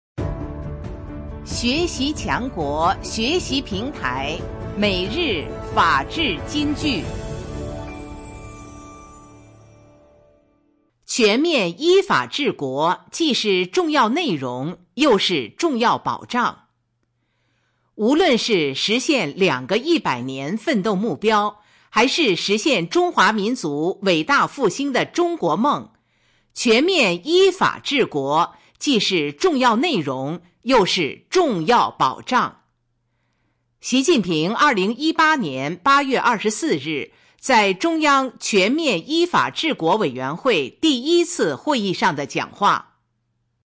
每日法治金句（朗读版）|全面依法治国既是重要内容，又是重要保障 _ 宪法 _ 福建省民政厅